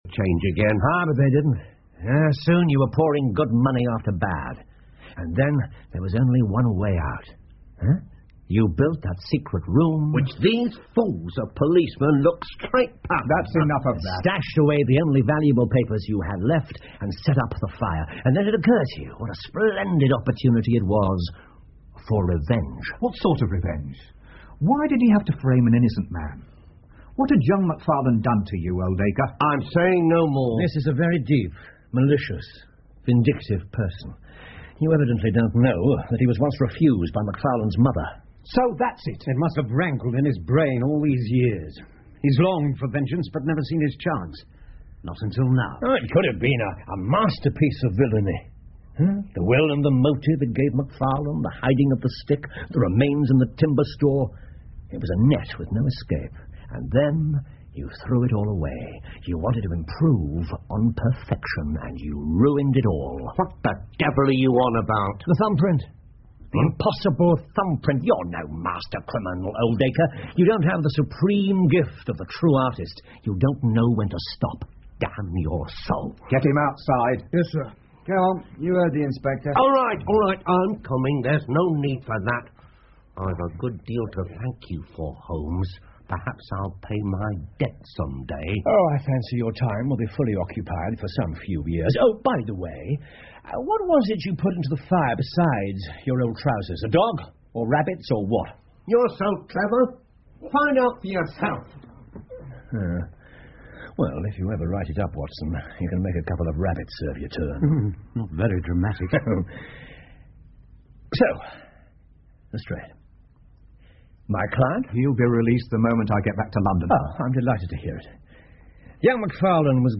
福尔摩斯广播剧 The Norwood Builder 9 听力文件下载—在线英语听力室